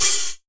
Chart Open Hat 04.wav